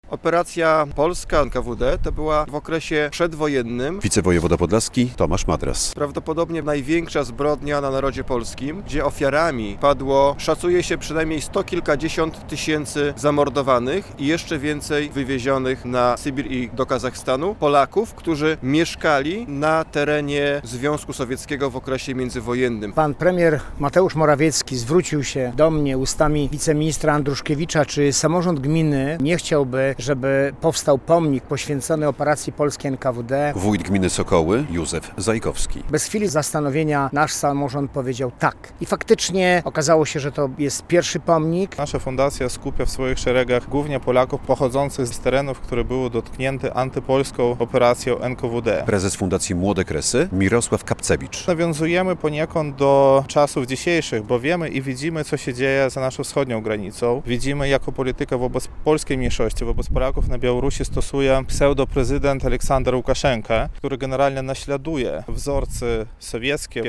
Wicewojewoda Podlaski Tomasz Madras mówi, że to jedna z najbardziej krwawych operacji skierowanych przeciw Polakom przed II wojną światową.